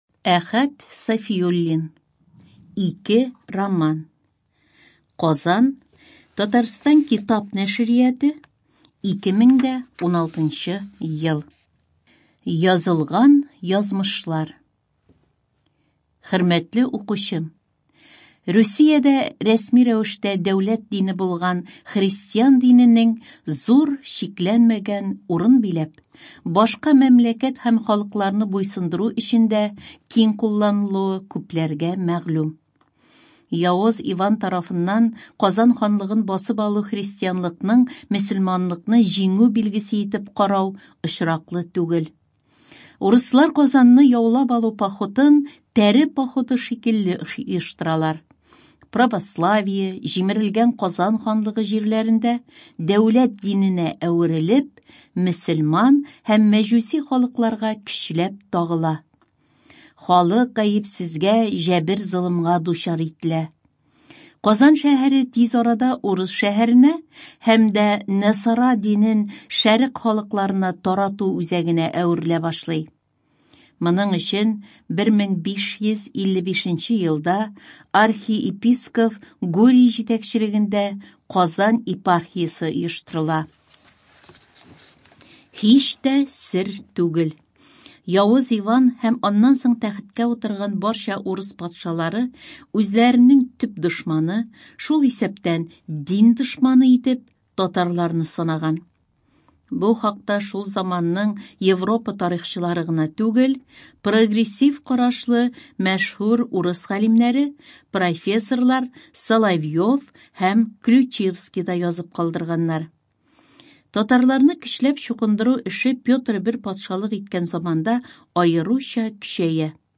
Студия звукозаписиТатарская республиканская специальная библиотека для слепых и слабовидящих